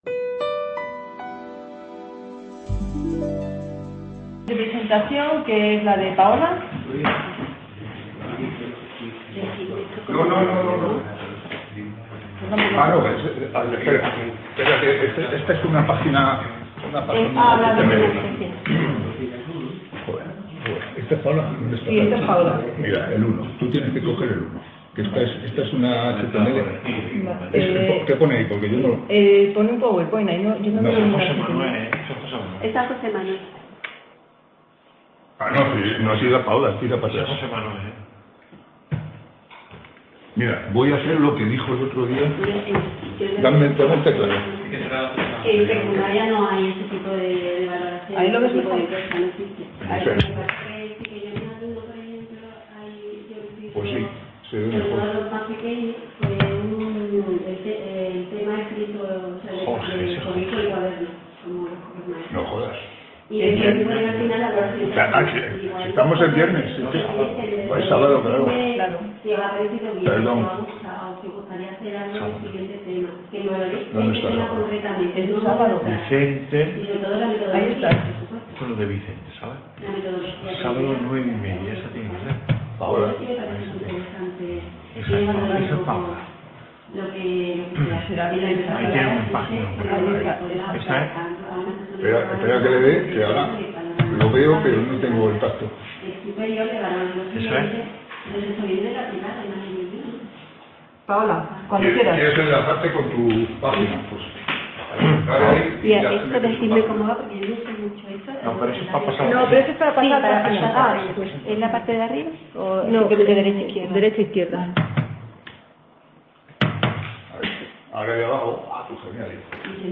Ponencia S-2ª Innovación matemática en EESS.
Sextas Jornadas de Experiencias e Innovación Docente en Estadística y Matemáticas (eXIDO22)